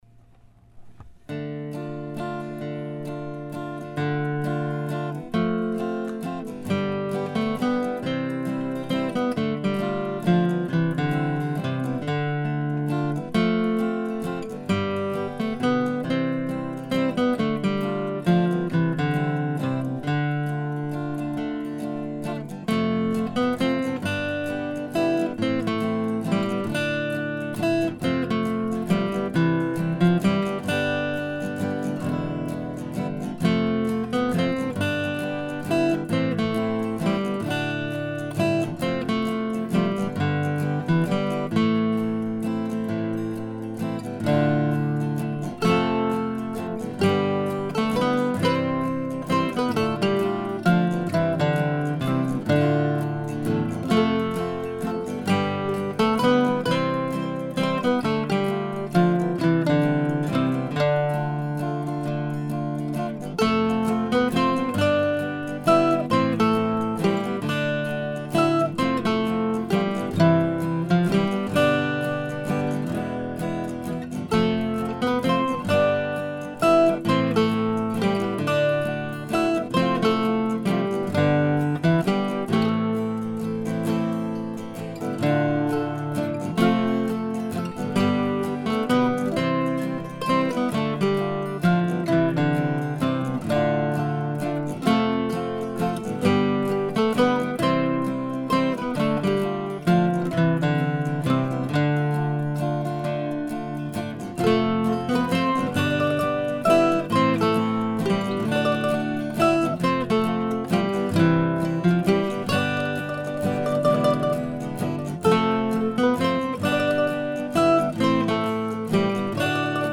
It's a slightly quirky waltz because the first strain has only six measures.